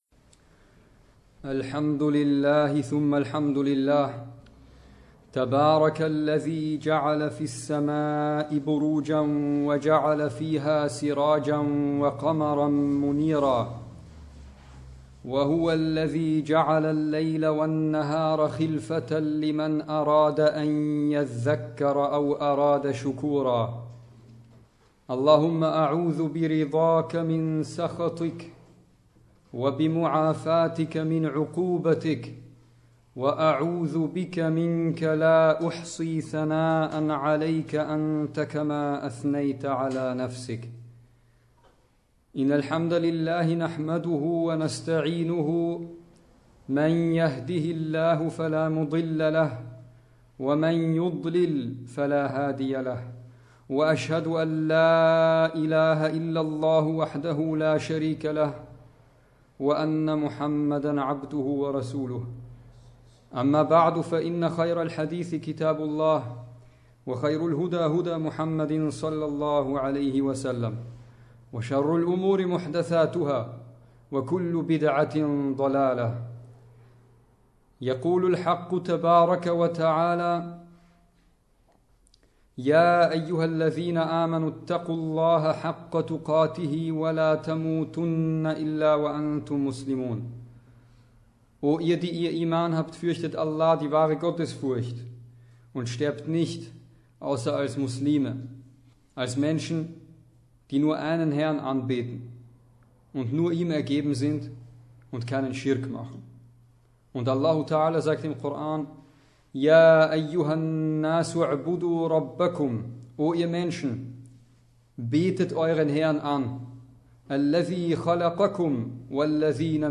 Freitagsansprache: Das Heiraten in Zeiten der Unwissenheit
Ursprünglicher Ort der Ansprache: al-Iman-Moschee in Wien
Die eigentliche Ansprache besteht aus zwei Teilen, dazwischen eine kurze Pause. Am Ende folgt das Gebet mit Rezitation von Versen aus dem Koran, welche meistens auch einen Bezug zum Thema haben oder in der Ansprache erwähnt werden. Die im deutschen Hauptteil der Ansprache zitierten arabischen Quelltexte unterbrechen den Redefluss nicht nennenswert und werden immer übersetzt.